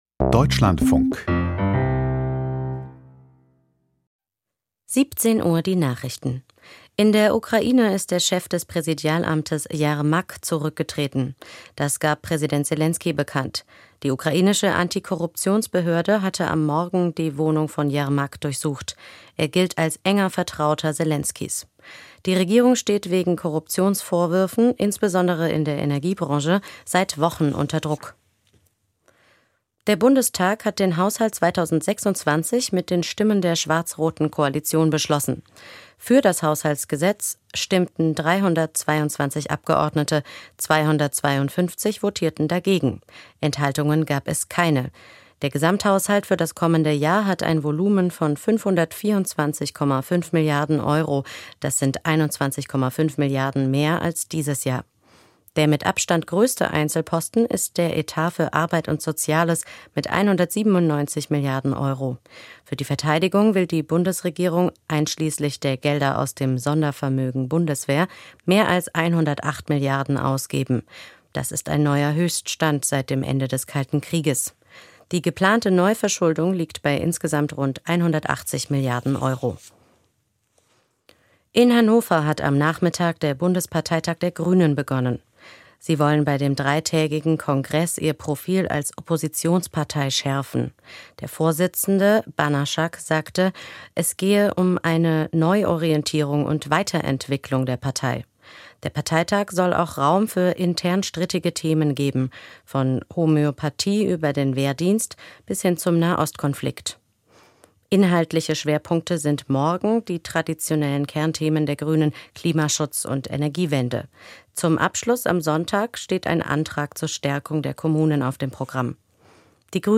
Die Nachrichten vom 28.11.2025, 16:59 Uhr